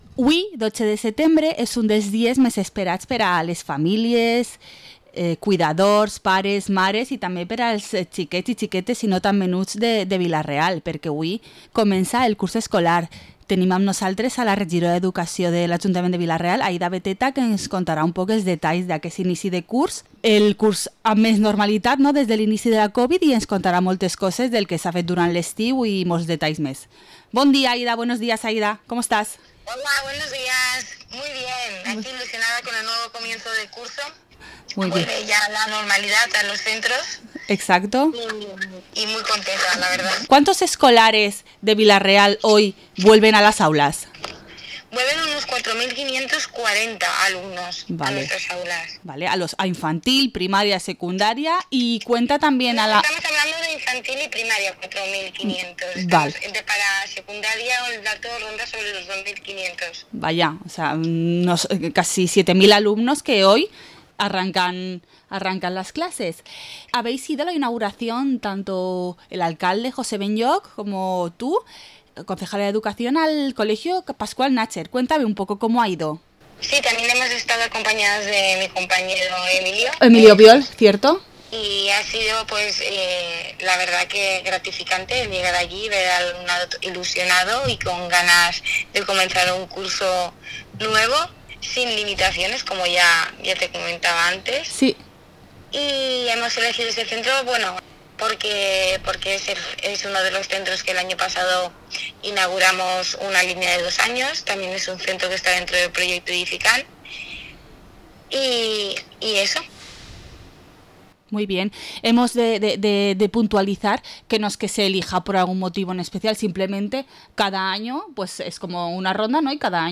Entrevista a la concejala de Educación de Vila-real, Aida Beteta